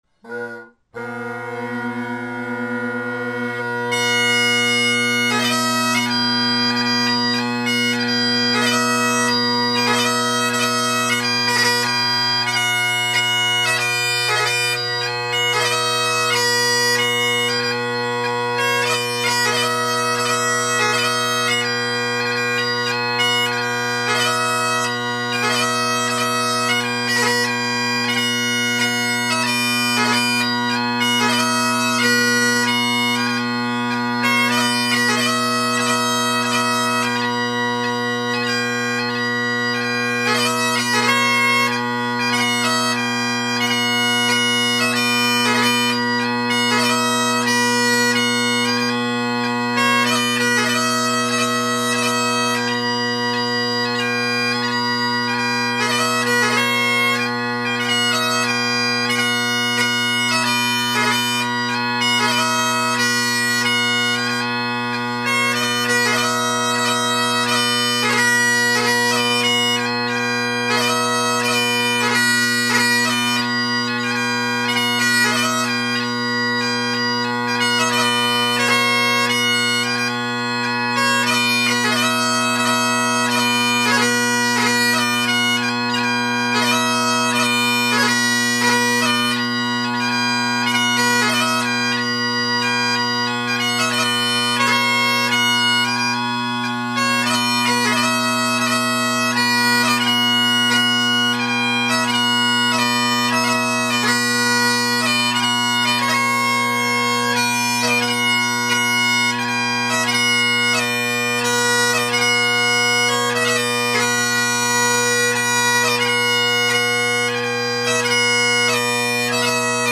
Great Highland Bagpipe Solo
There’s tape on high G, that’s it.
I haven’t practiced more than once a week (at band practice) for the last couple months (you might notice this is my first post in 4 months) so I’m a bit rusty.
Kron Medalist chanter, Melvin chanter reed, Chris Terry pipes with Rocket drone reeds.
Top hand sounds balanced and not at all thin. G is good to my humble ear.